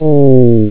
sfx_damage.wav